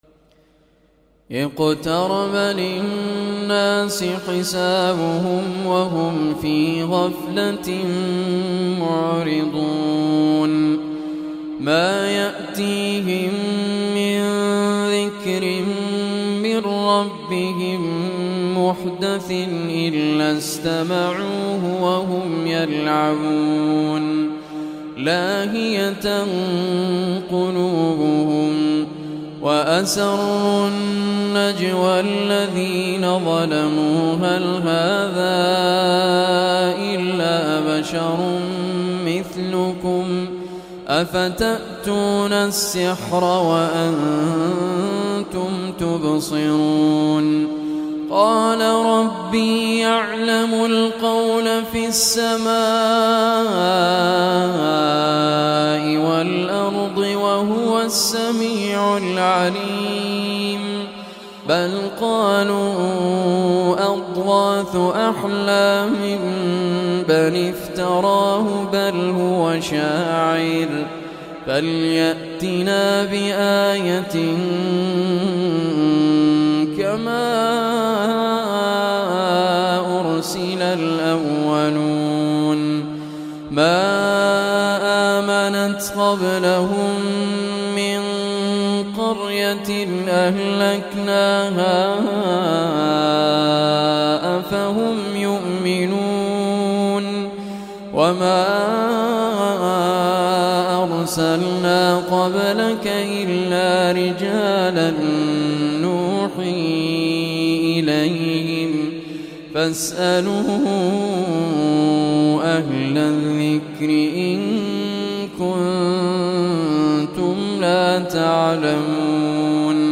Surah Anbiya Recitation by Raad Al Kurdi
Surah Anbiya, listen or play online mp3 tilawat/recitation in the beautiful voice of Sheikh Raad Al Kurdi.